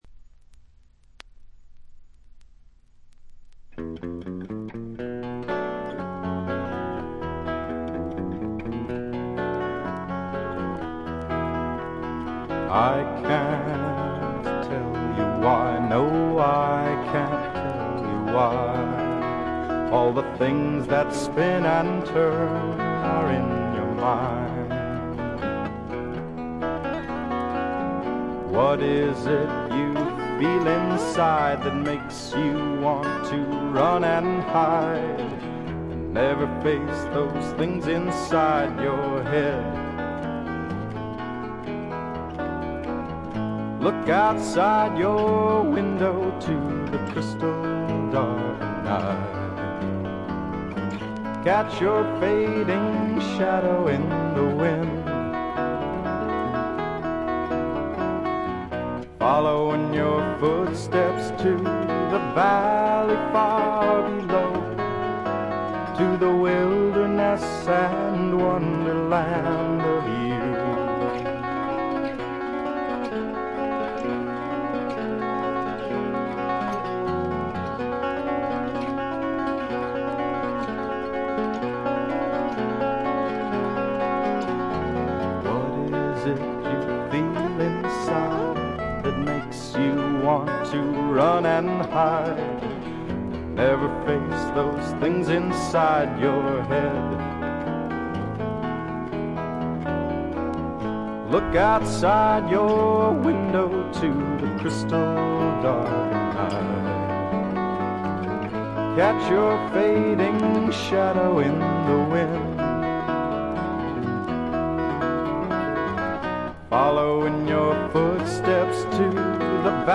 個人的にはジャケットの写真にあるようにフルアコ2台のエレクトリック・ギターの音が妙にツボに来ます。
試聴曲は現品からの取り込み音源です。
Guitar, Banjo, Vocals